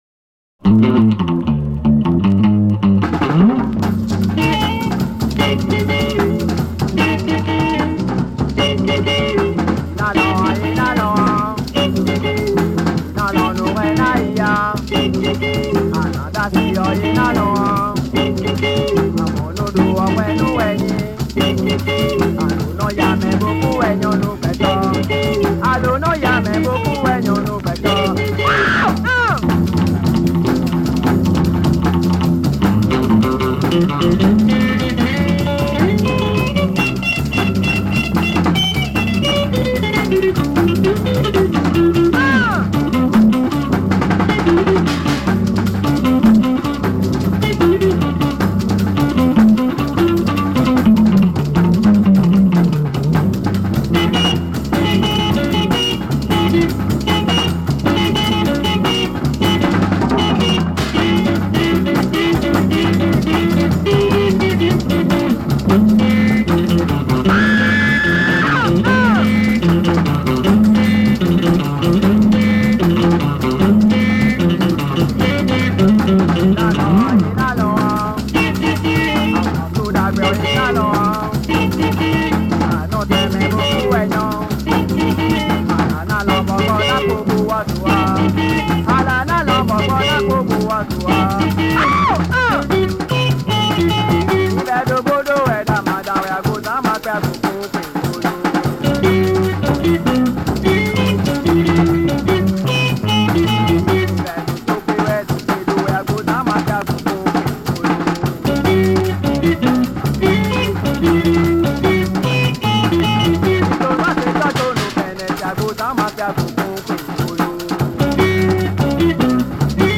This song is punk rock.
It’s true, Benin jerk is pure punkrock !